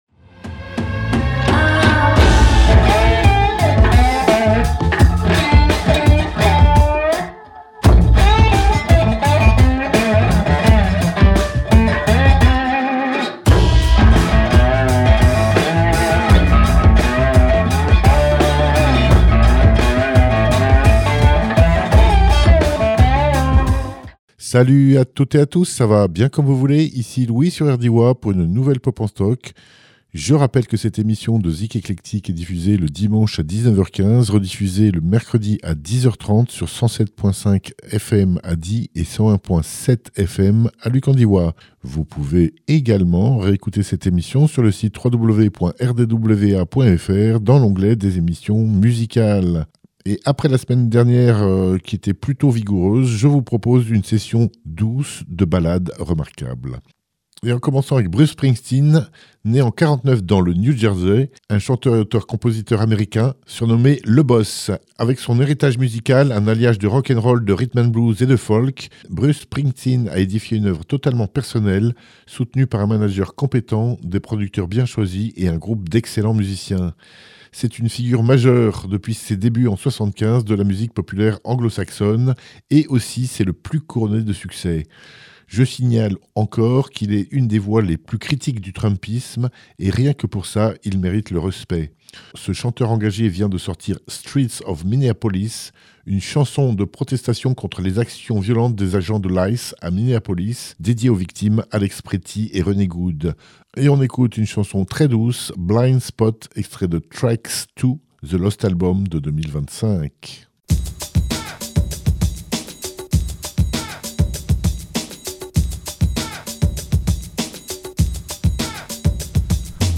folk , rock